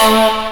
Index of /90_sSampleCDs/Techno_Trance_Essentials/CHOIR
64_22_voicesyn-A.wav